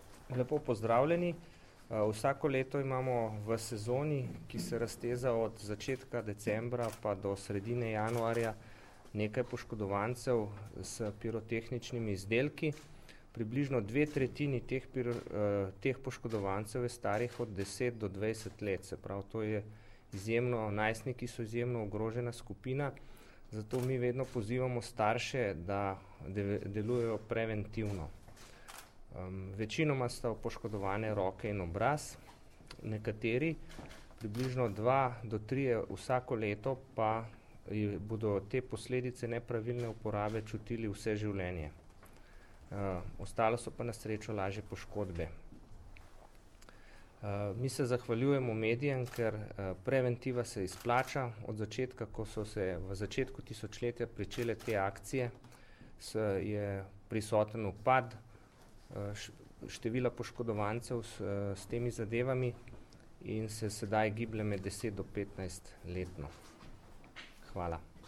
Letošnjo akcijo Bodi zvezda, ne meči petard, pa tudi različne vidike uporabe pirotehnike ter nevarnosti in neprijetnosti, ki jih ta lahko povzroča, so na današnji novinarski konferenci predstavili: